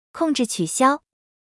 audio_disengage.wav